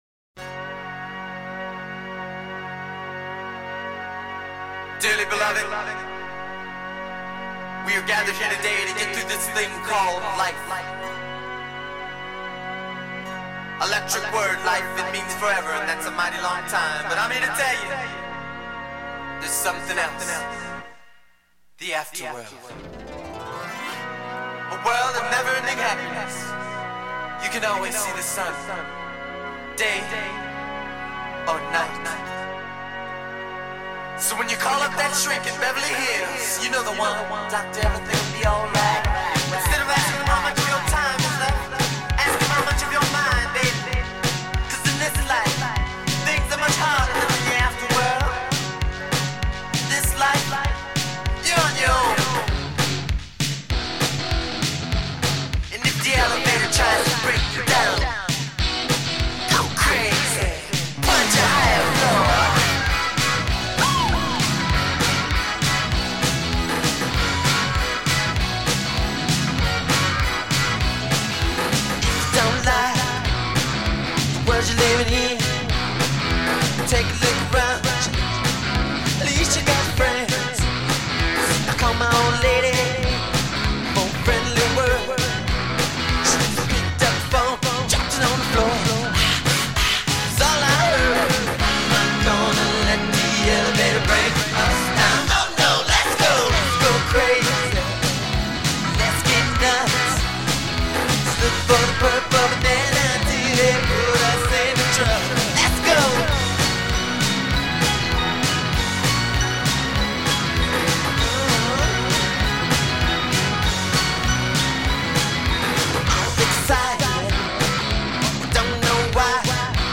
Pop, Rock, Funk / Soul, Soundtrack